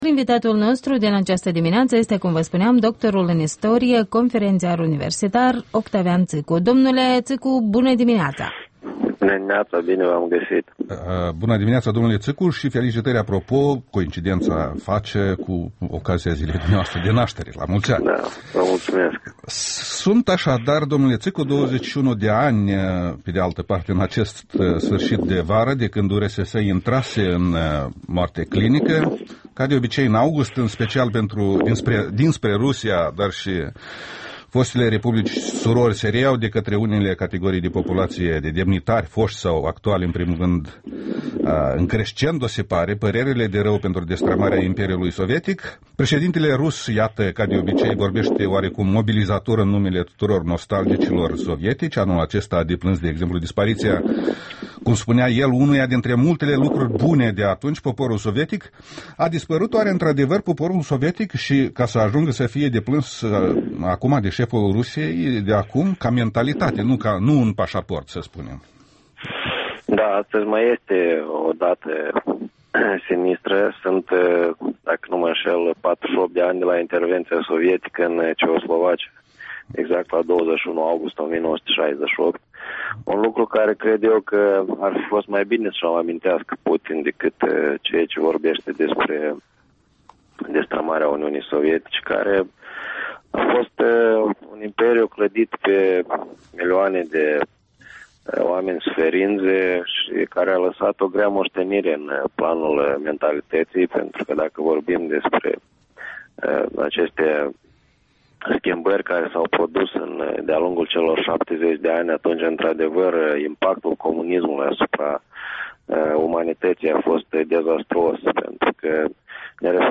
Interviul dimineții la EL: cu istoricul Octavian Țîcu